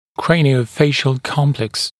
[ˌkreɪnɪə(u)ˈfeɪʃl ‘kɔmpleks][ˌкрэйнио(у)ˈфэйшл ‘комплэкс]черепно-лицевой комплекс